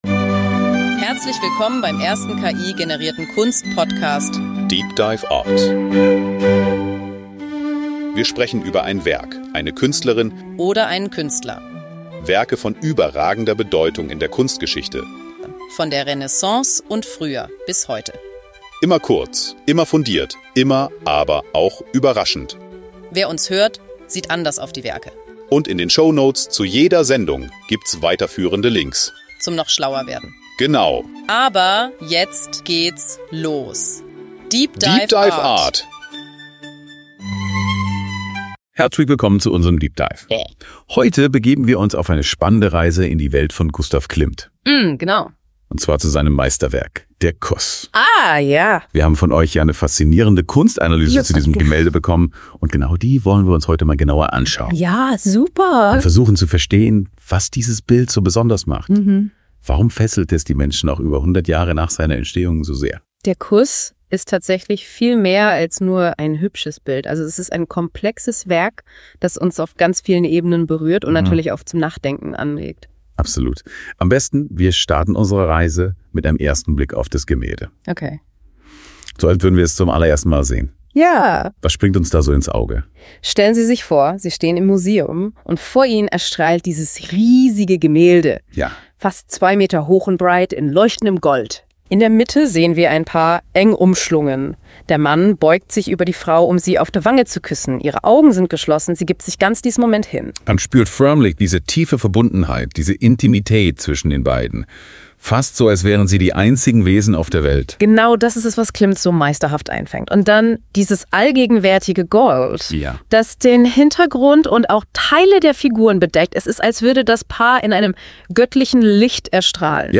Die Kunst wird als ein offenes Fenster betrachtet, das den Betrachter zum Nachdenken anregt und verschiedene Interpretationen zulässt. deep dive art ist der erste voll-ki-generierte Kunst-Podcast.
Die beiden Hosts, die Musik, das Episodenfoto, alles.